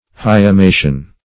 Search Result for " hyemation" : The Collaborative International Dictionary of English v.0.48: Hyemation \Hy`e*ma"tion\, n. [L. hiematio.] 1.